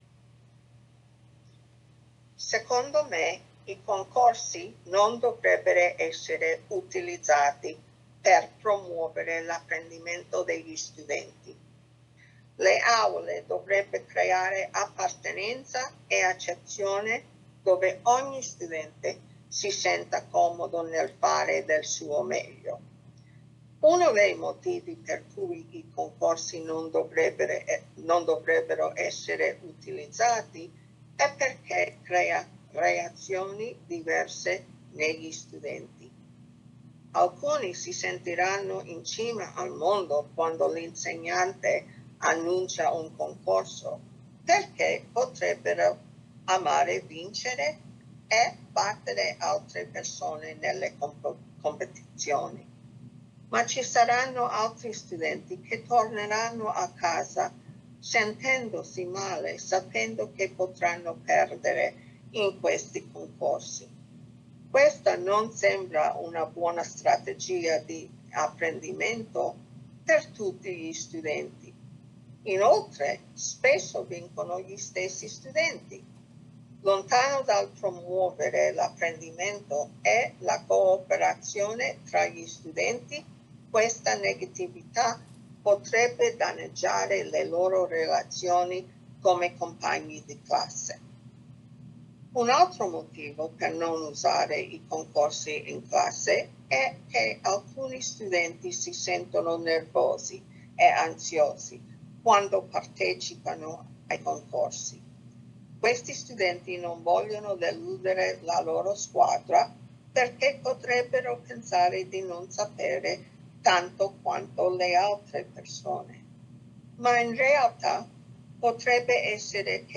Presentational Speaking: Italian
The response effectively communicates clear and logically sequenced ideas delivered with a consistent flow of speech, few pauses, intelligible pronunciation and appropriate intonation.
Examples of such errors include some inconsistency in the flow of speech and a few pauses.